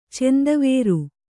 ♪ cendavēru